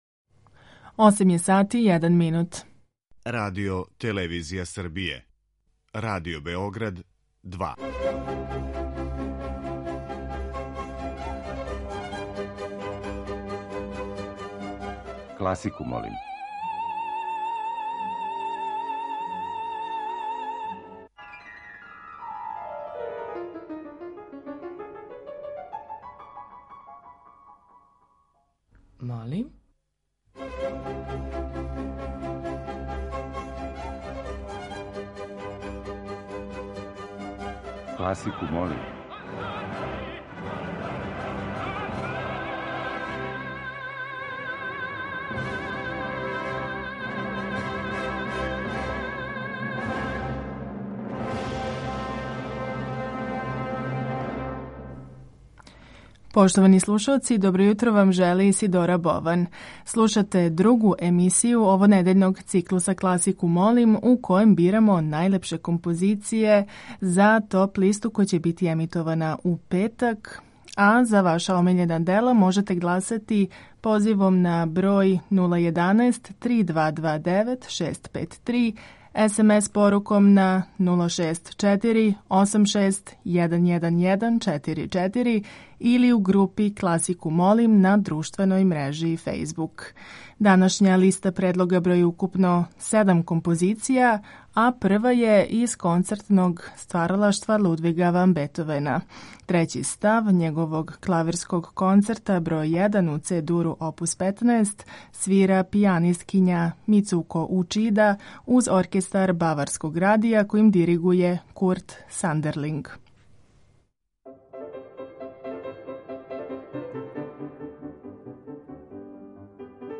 Барокне свите